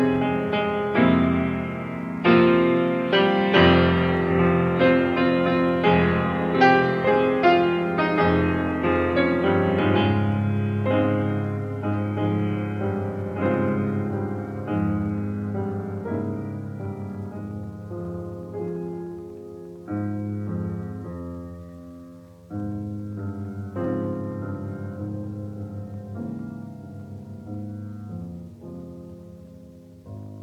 "enPreferredTerm" => "Musique de chambre"